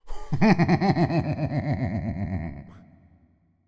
Laugh_Evil_01
epic fantasy fear frightening frightful game gamedev gamedeveloping sound effect free sound royalty free Funny